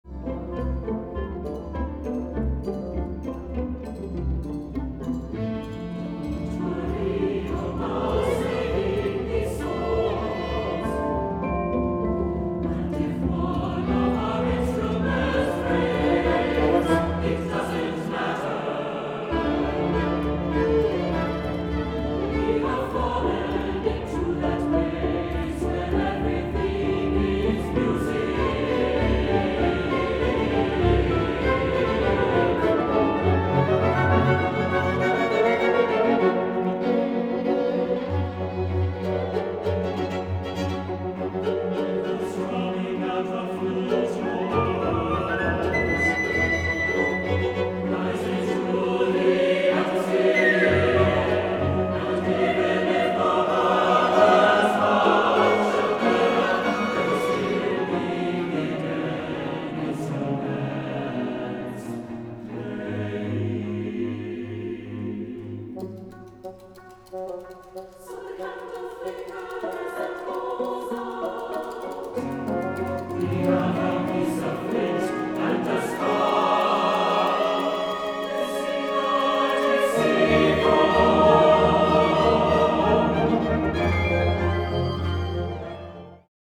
2 violins, 1 viola, 1 cello, 1 contrabass